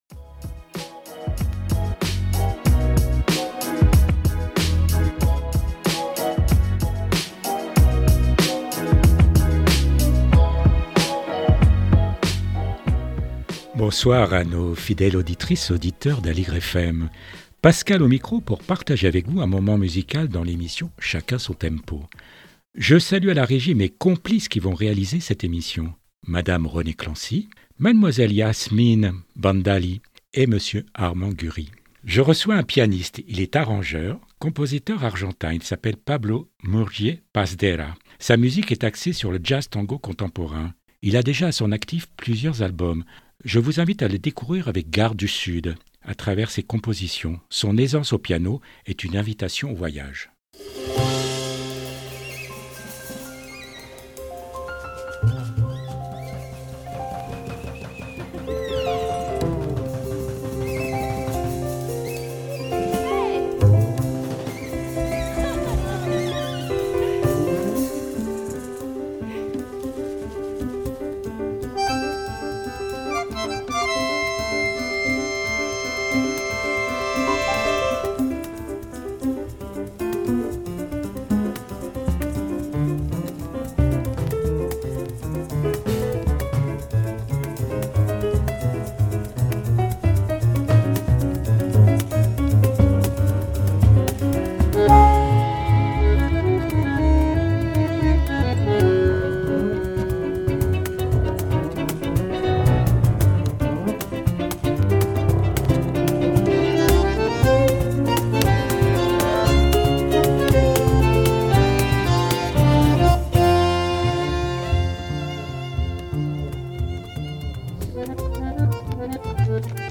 Sa musique est axée sur le jazz tango contemporain